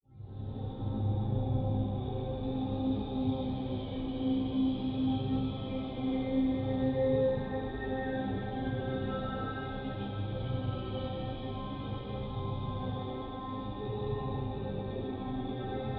Creepy Dark Room Ambiance
Tags: Horror FX Sound